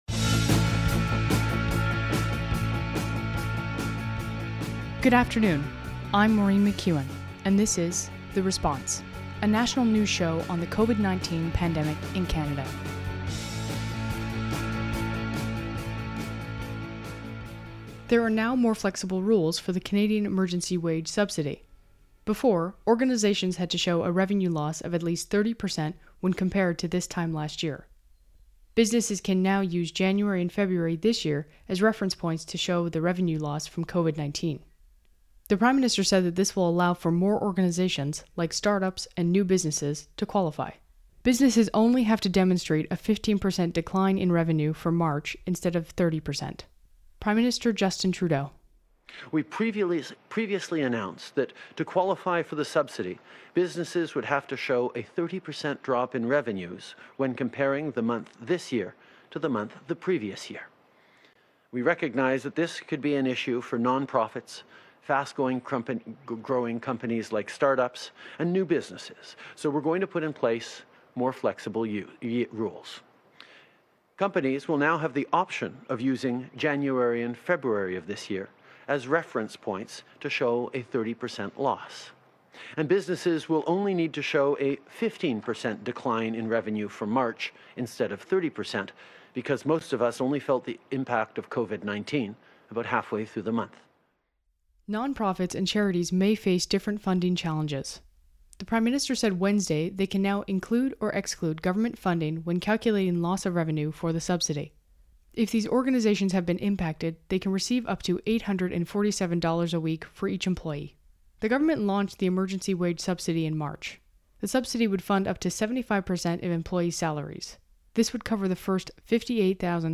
National News Show on COVID-19
Type: News Reports